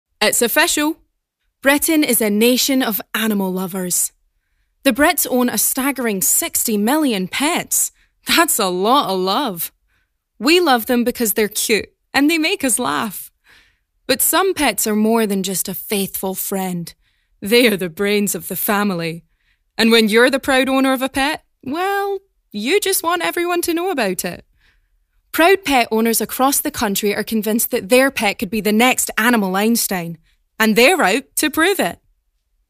Documentary Showreel
Female
Scottish
American Standard
Bright
Friendly
Voice Next Door
Upbeat